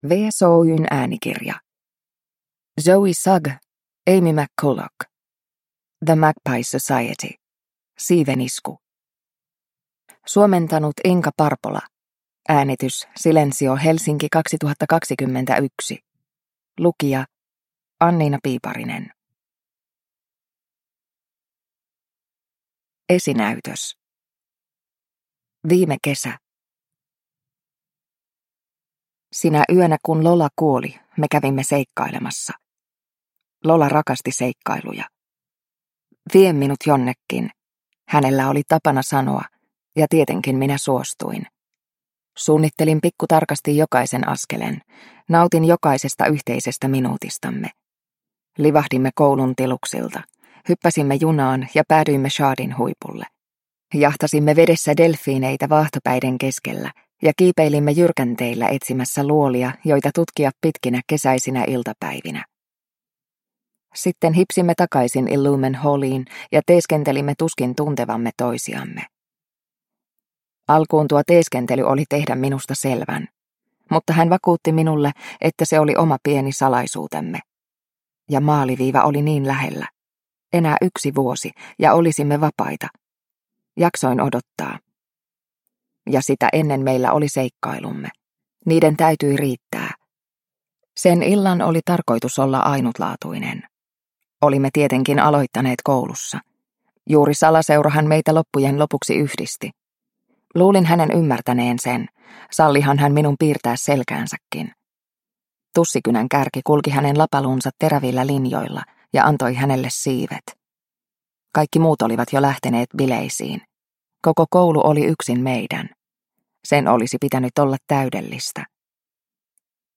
The Magpie Society: Siivenisku – Ljudbok – Laddas ner